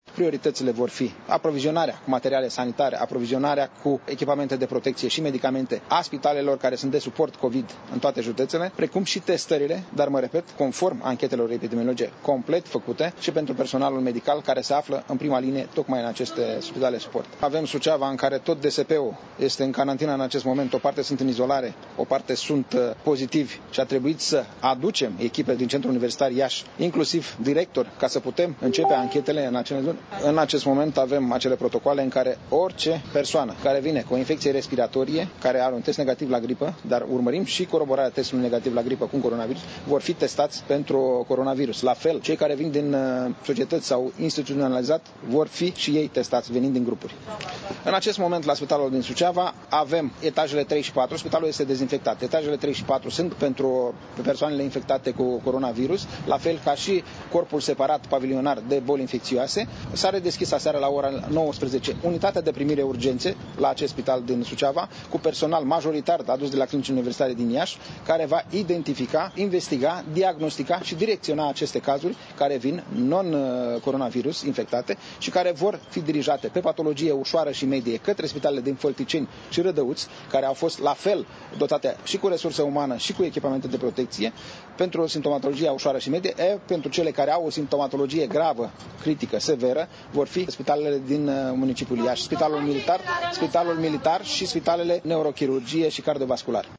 Ministrul sănătăţii, Nelu Tătaru, a declarat, dimineaţă, că urmează săptămâni grele şi că se iau toate măsurile pentru dotarea spitalelor, pentru echiparea şi testarea personalului medical.